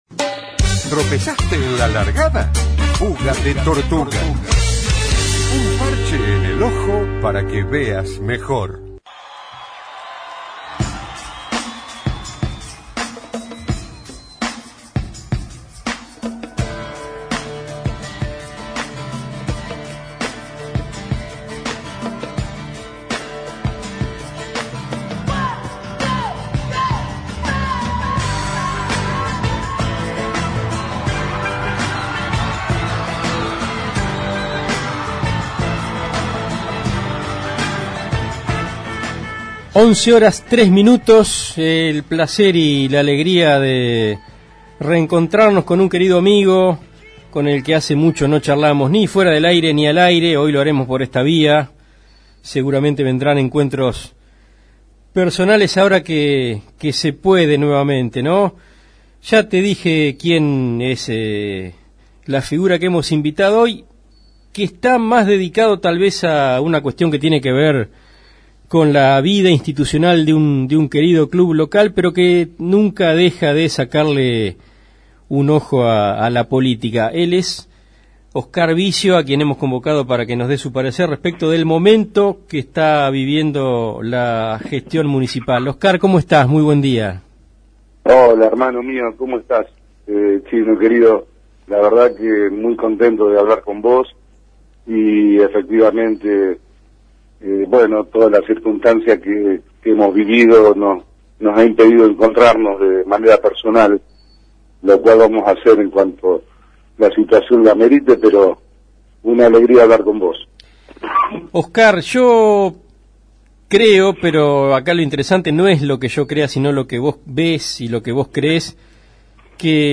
periodista